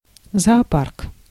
Ääntäminen
Tuntematon aksentti: IPA: /zɐːˈpark/